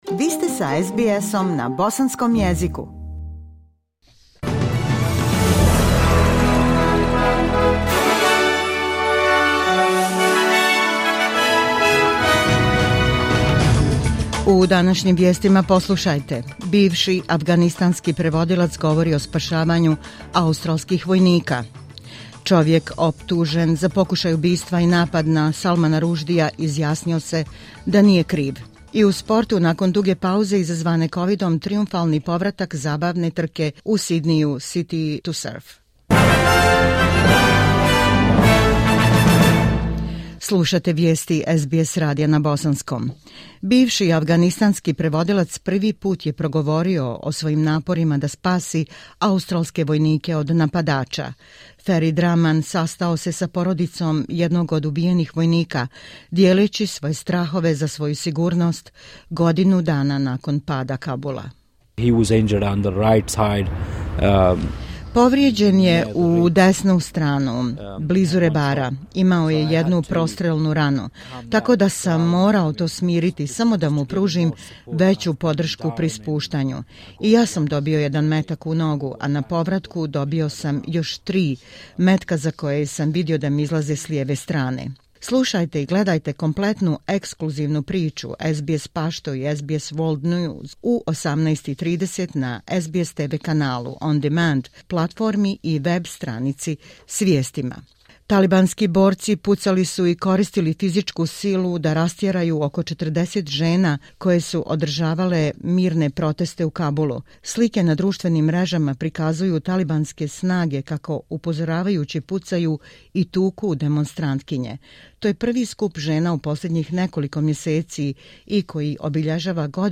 Vijesti SBS radija na bosanskom jeziku.